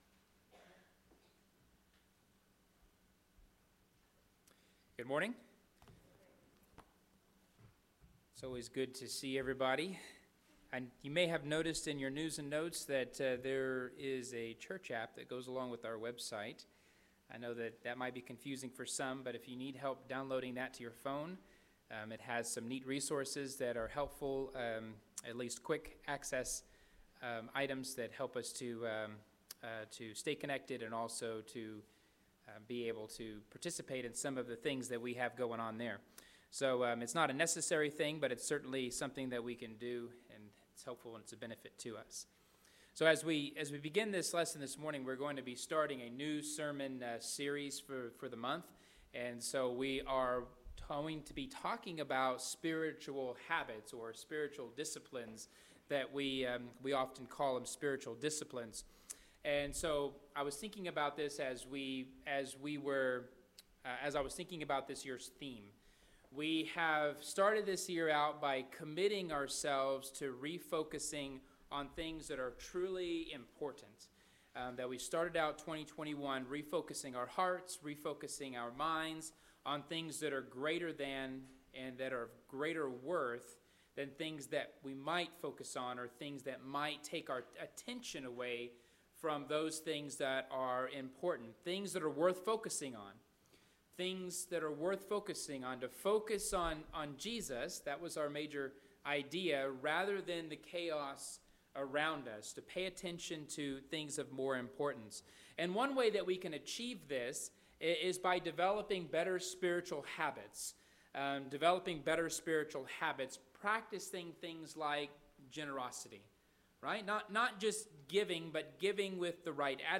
All Sermons Spiritual Habits